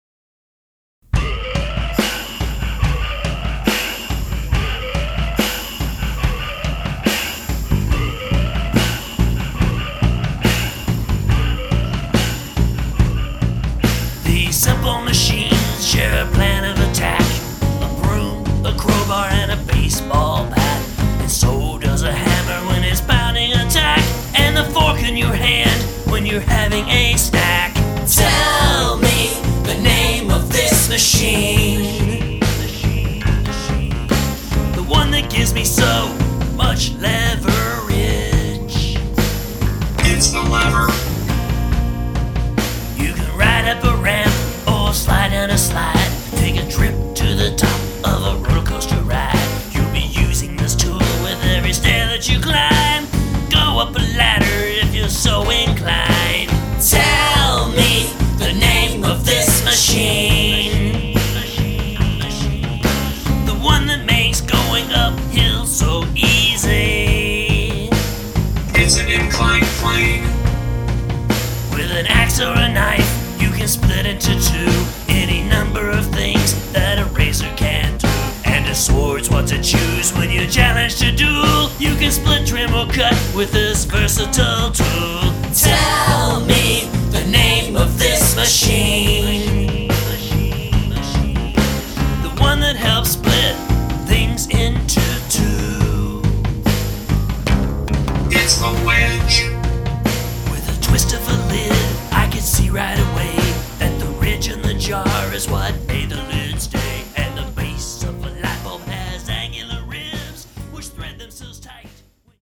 only more rockin' and trippier.